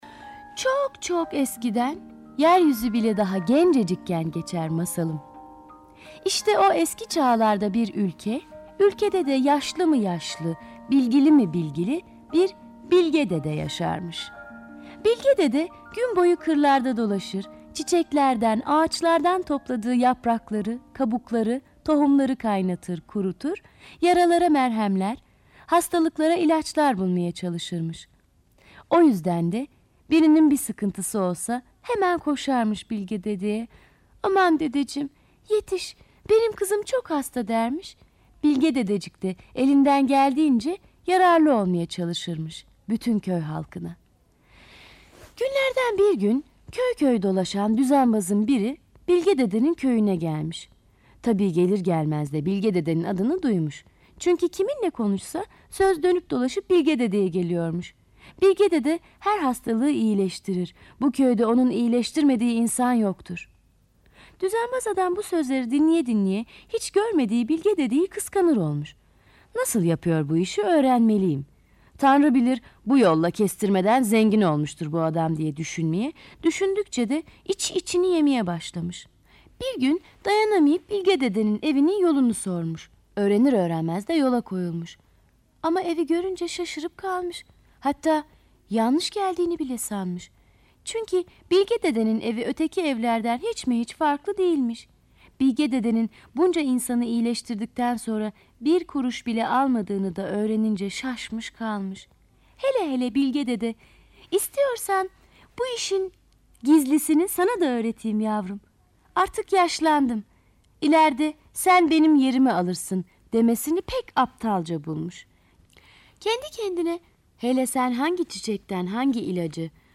Bilge dede sesli masalı, mp3 dinle indir
Sesli Çocuk Masalları